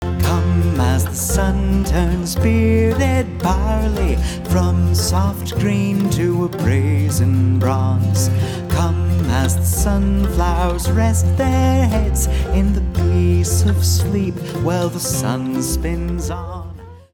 Celtic-style harvest hymn